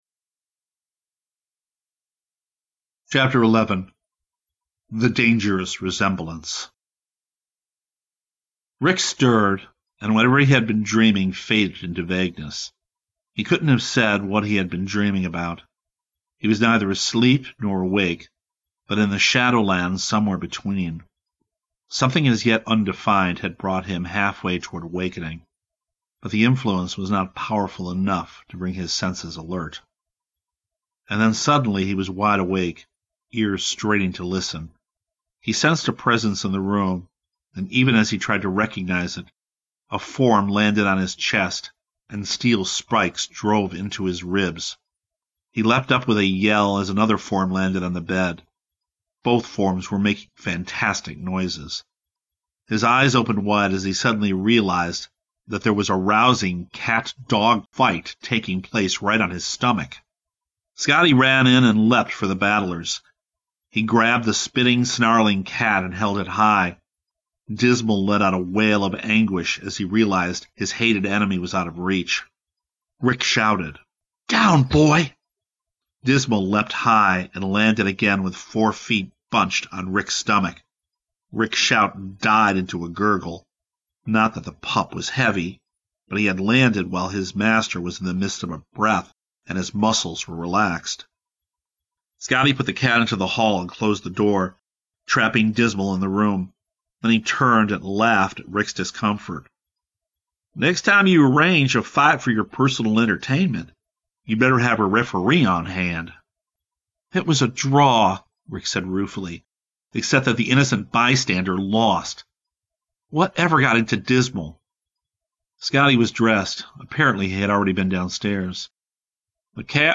This series of podcasts/dramatic readings of kids public domain literature.